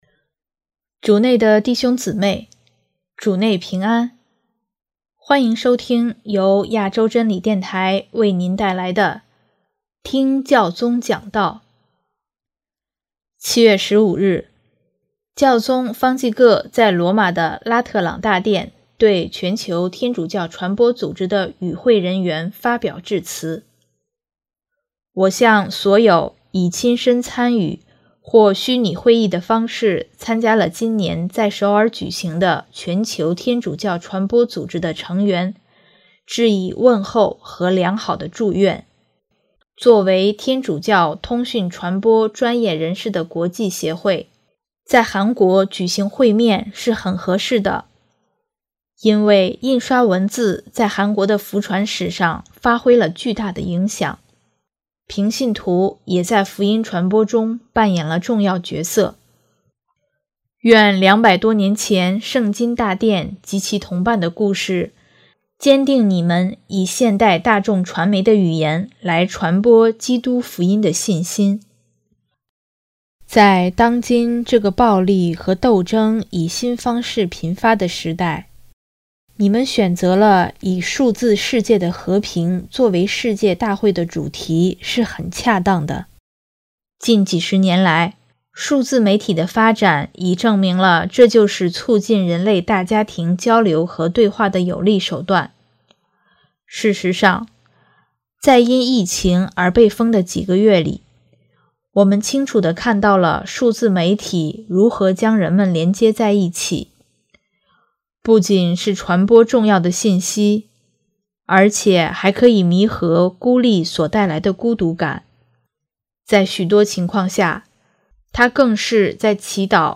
7月15日，教宗方济各在罗马的拉特朗大殿对全球天主教传播组织的与会人员发表致辞。